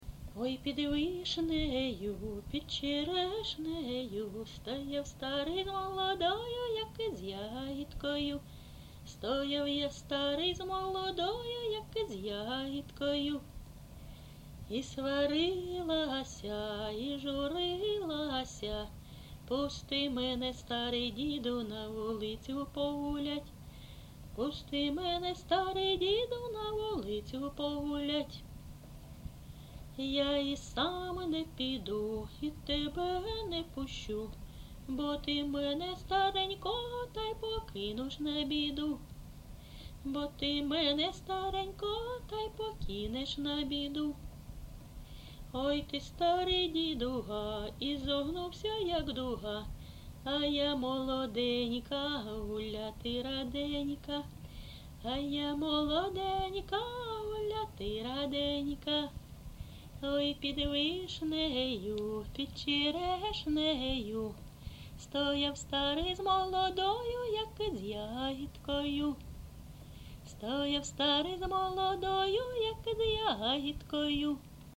ЖанрПісні з особистого та родинного життя, Жартівливі
Місце записум. Ровеньки, Ровеньківський район, Луганська обл., Україна, Слобожанщина